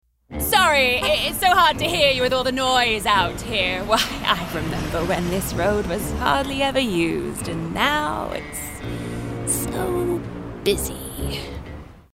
Animation V/O - Standard British Accent
Uptight Busy Body